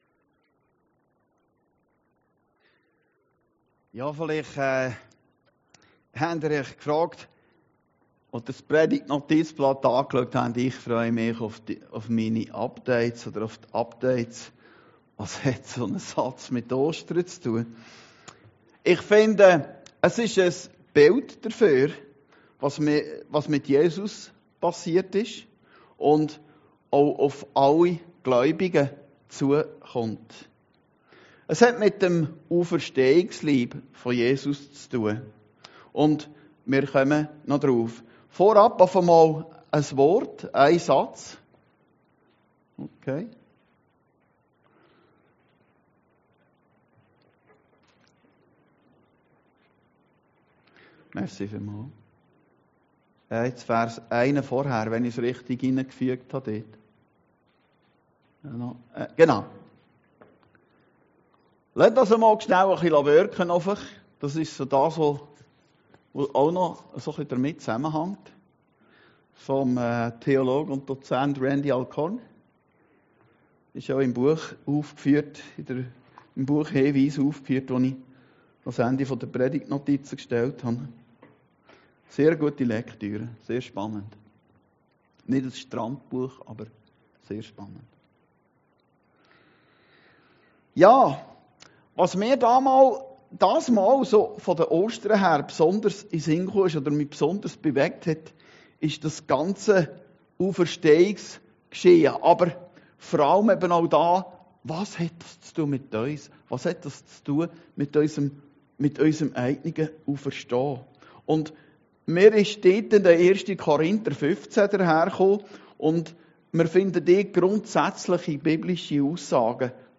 Predigten Heilsarmee Aargau Süd – Ostern - Ich freue mich auf die Updates